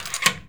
door_lock_slide_05.wav